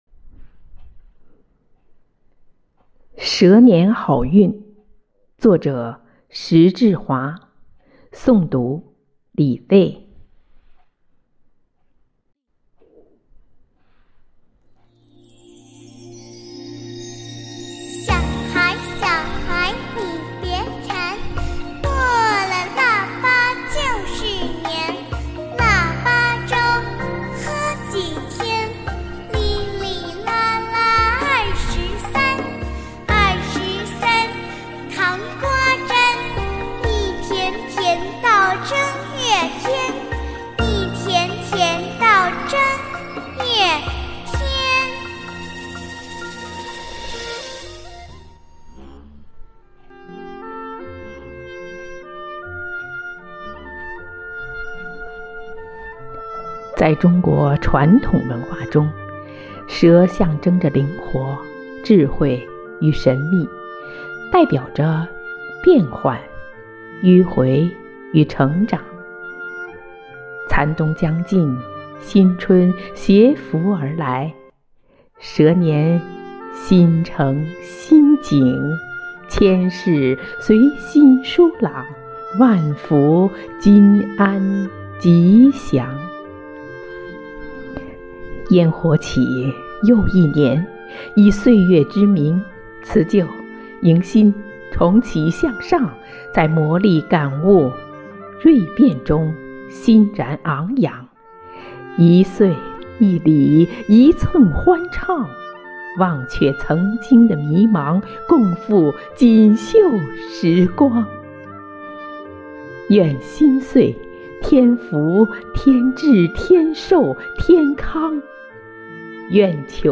每日《女兵诵读》蛇年好运